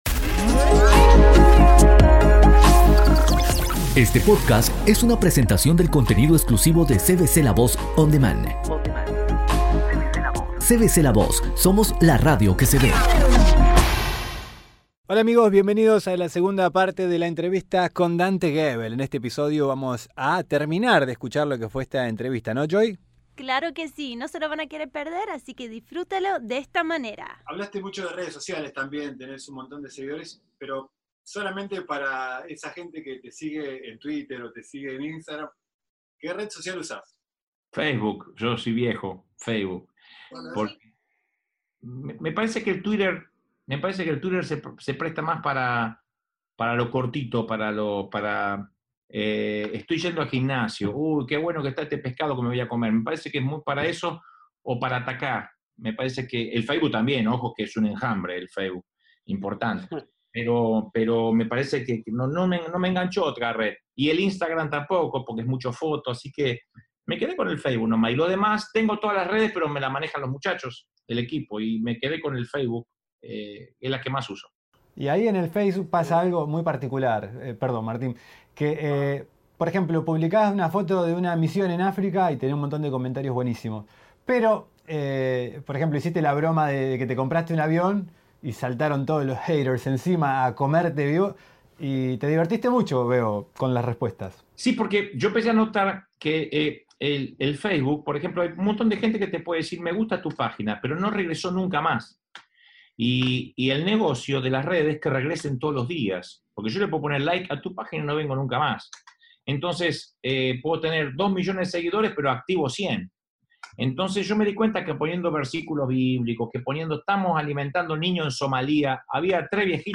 Entrevista a Dante Gebel - Parte 2